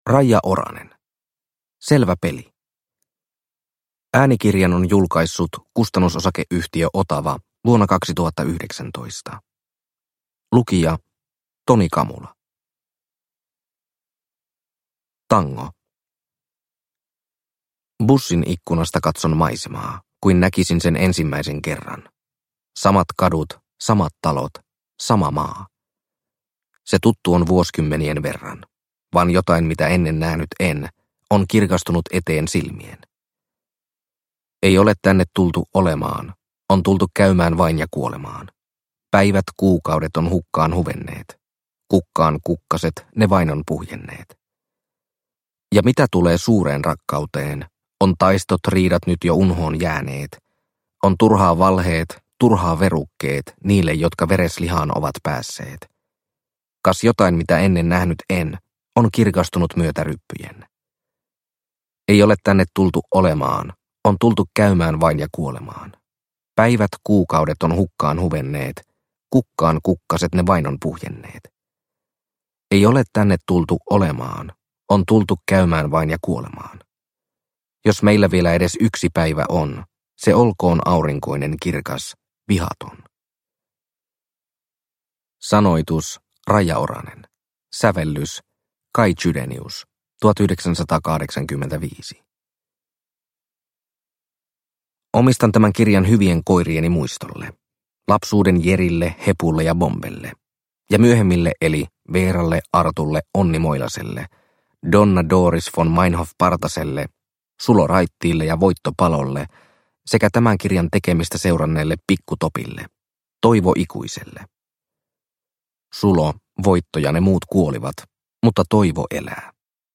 Selvä peli – Ljudbok – Laddas ner